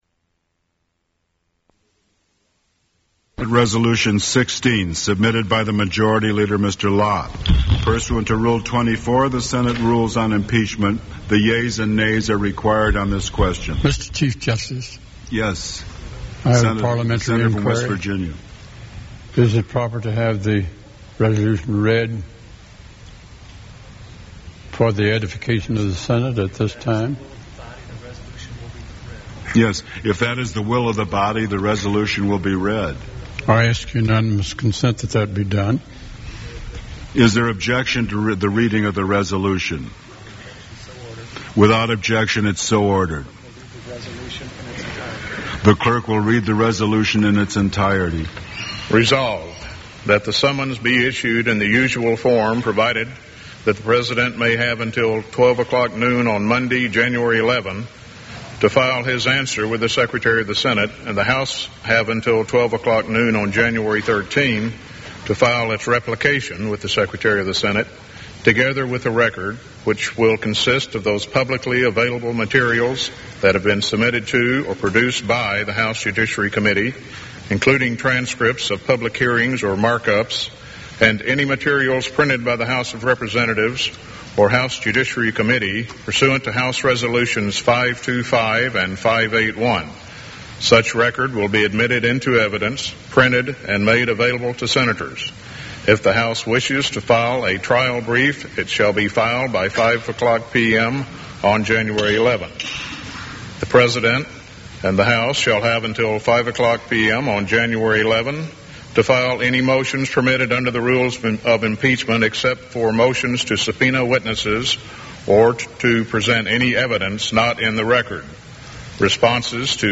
The request to read the Articles of Impeachment is followed by a voice vote of all senators to accept them, followed by Majority Leader Trent Lott's announcement that the session is adjourned.
Committee on the Judiciary Subjects Clinton, Bill, 1946- Impeachments Trials (Impeachment) United States Material Type Sound recordings Language English Extent 00:20:00 Venue Note Broadcast on CNN-TV, News, Jan. 8, 1999.